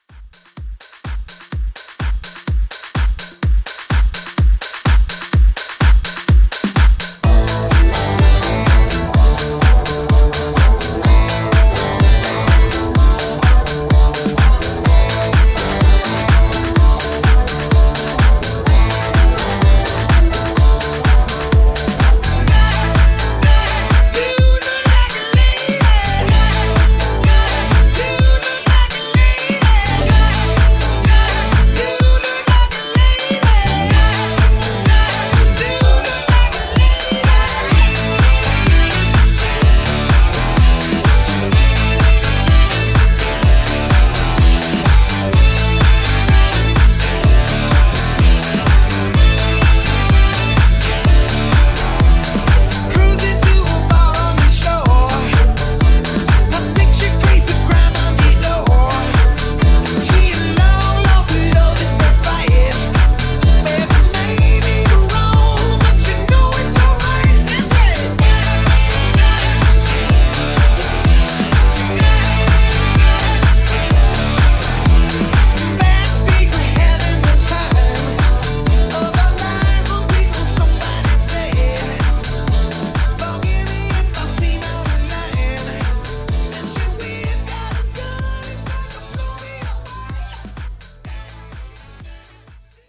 Genres: 80's , RE-DRUM , ROCK
Clean BPM: 126 Time